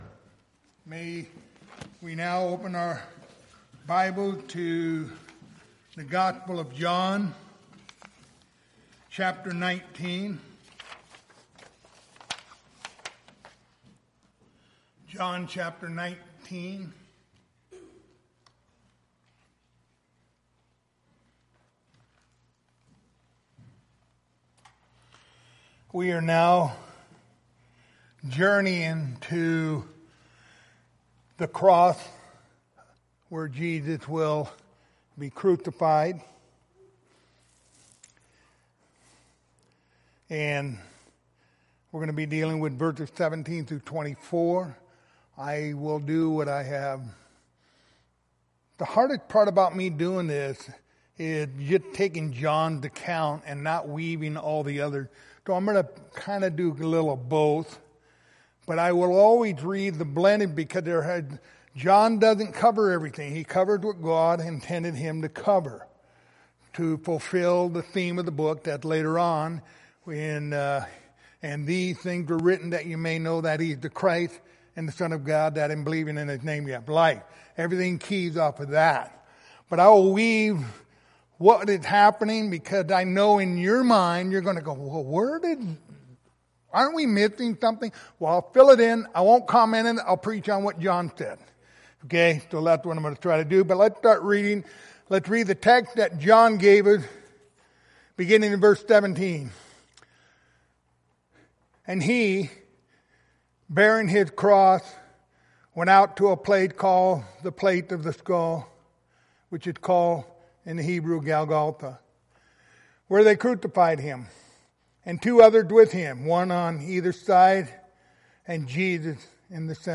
The Gospel of John Passage: John 19:17-24 Service Type: Wednesday Evening Topics